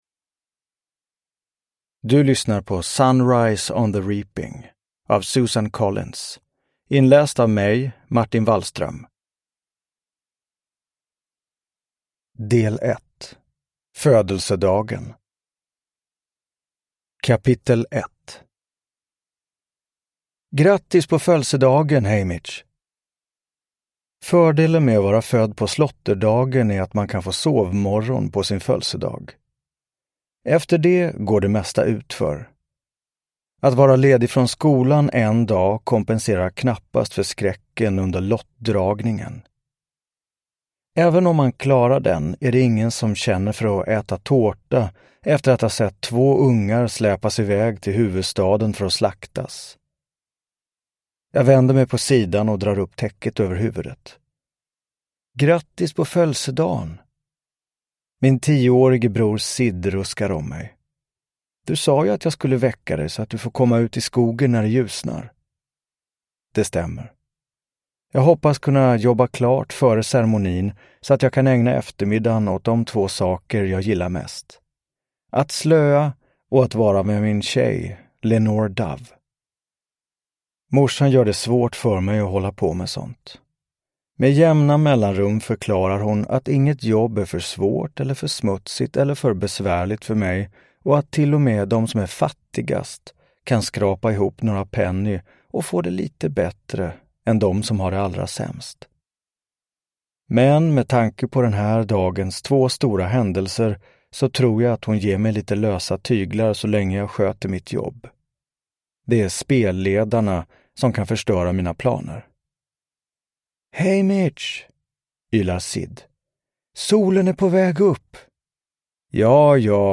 Sunrise on the Reaping (Svensk utgåva) – Ljudbok
Uppläsare: Martin Wallström